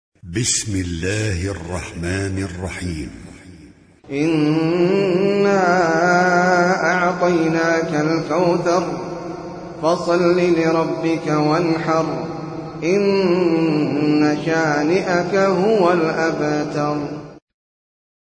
Sûrat Al-Kauther (A River in Paradise) - Al-Mus'haf Al-Murattal